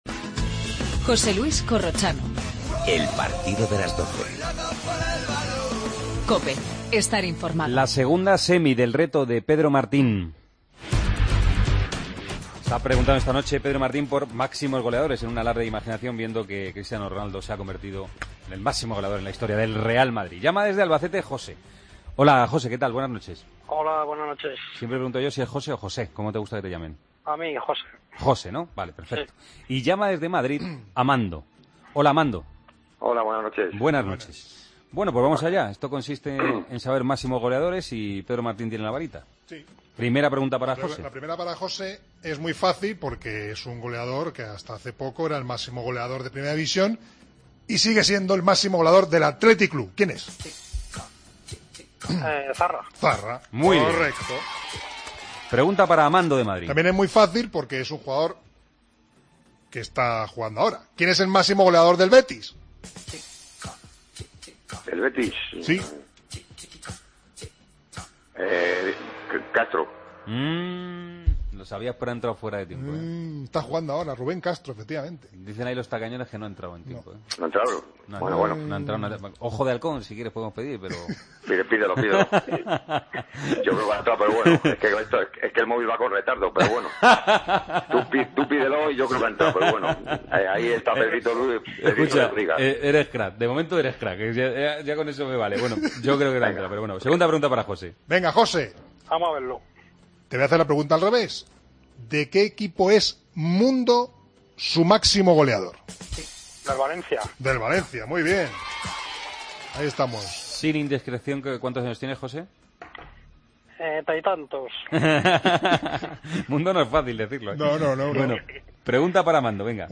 Entrevista a Bruno, jugador del Villarreal, que vuelve a una lista de Del Bosque.
Hablamos con Guaita, portero del Getafe, tras el empate entre el Celta y el conjunto madrileño. Previas del resto de la jornada de este sábado.